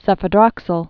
(sĕfə-drŏksəl)